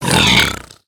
sounds / mob / piglin / death1.ogg
death1.ogg